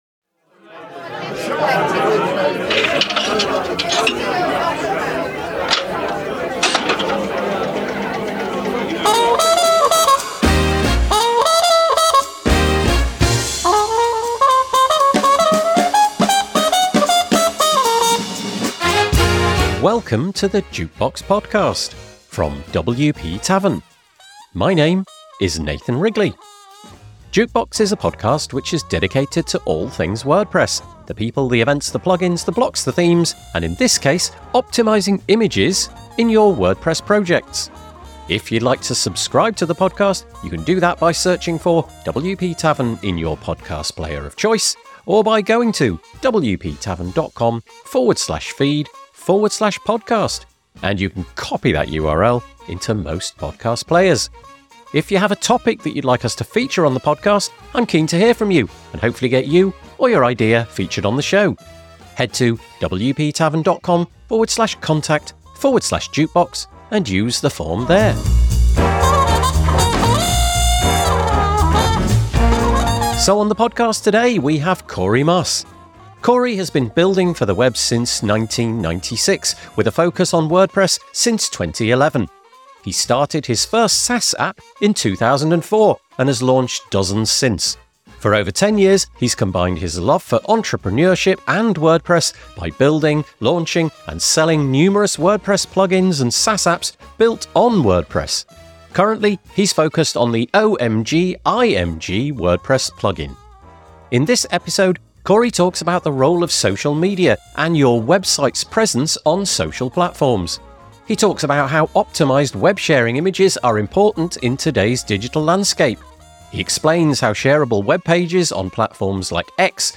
The WP Tavern Jukebox is a podcast for the WordPress community. We interview people who are pushing change in how WordPress evolves. Plugins, Blocks, Themes, Community, Events, Accessibility and Diversity; we try to cover all the bases.